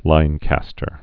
(līnkăstər)